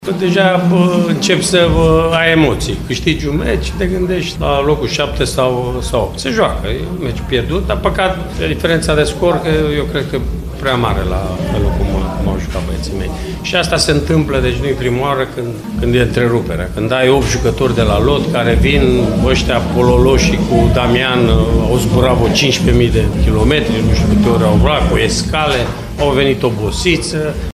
Înfrângerea de la Iași o coboară pe UTA periculos de aproape de zona retrogradării. Mircea Rednic a vorbit însă despre o înfrângere oarecum așteptată, dată fiind oboseala jucătorilor care s-au întors târziu de la echipele lor naționale: